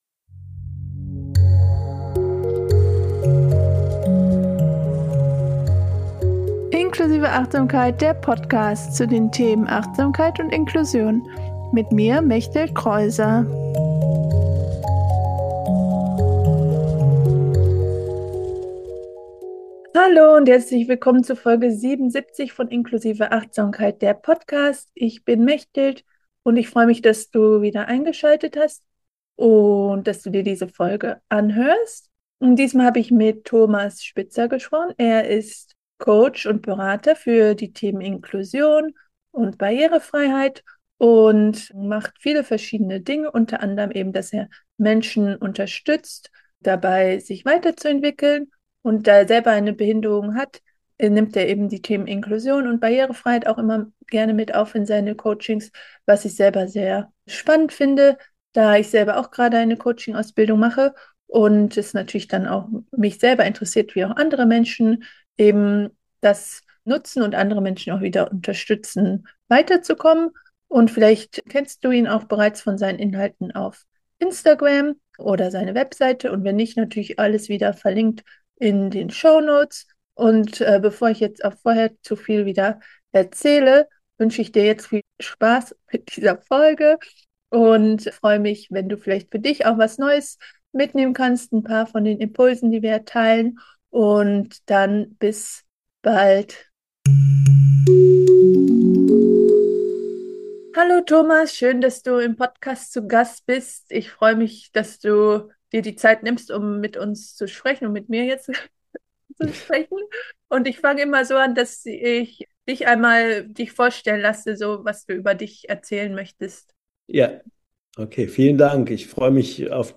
Inklusion und Barrierefreiheit im Coaching - Interview